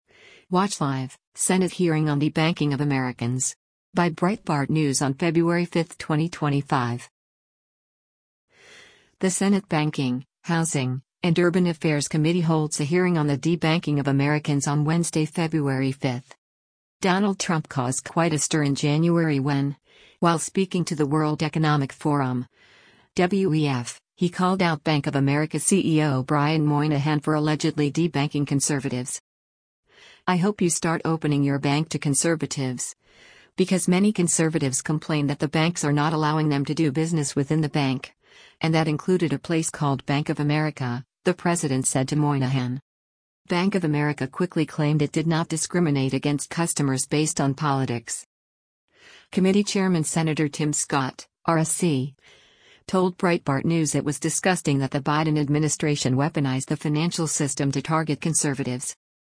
The Senate Banking, Housing, and Urban Affairs Committee holds a hearing on the debanking of Americans on Wednesday, February 5.